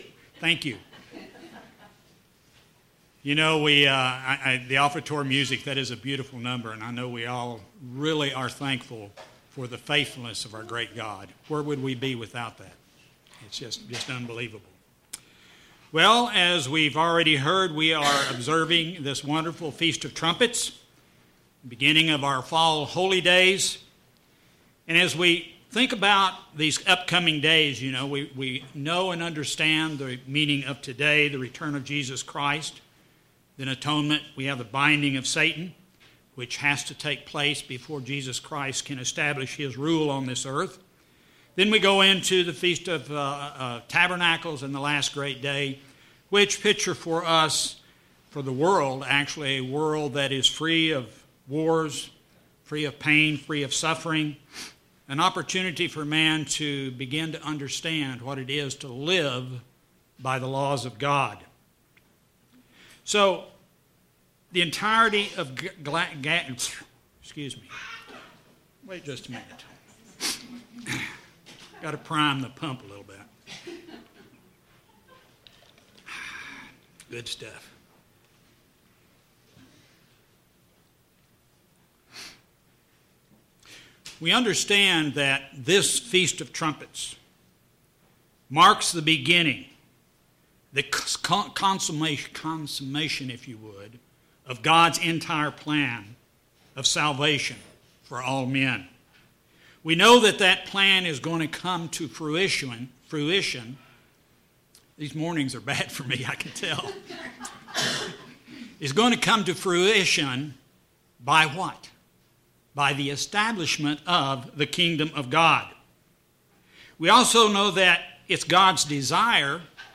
In this sermon, the speaker goes through the importance of repentance and how it applies to gospel.